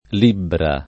libbra [ l & bbra ] s. f. («misura di peso»)